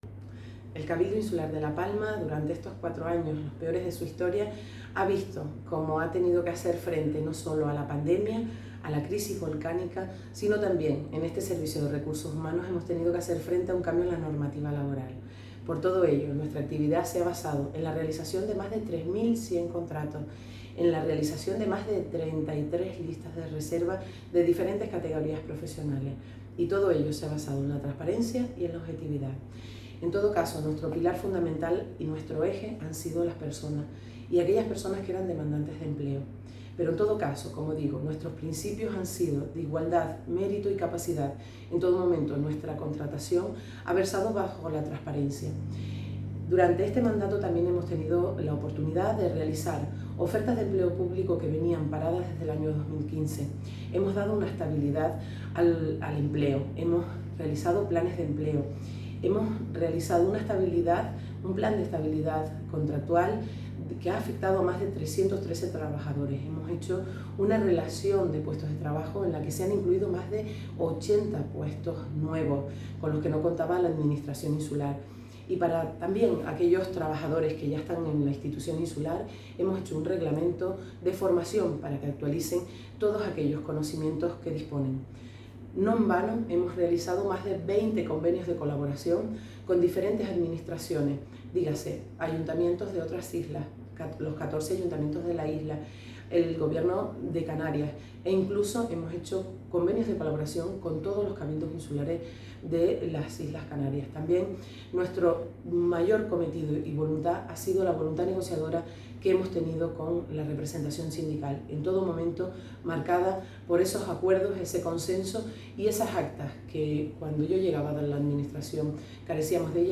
Declaraciones_audio_Nayra_Castro_RRHH.mp3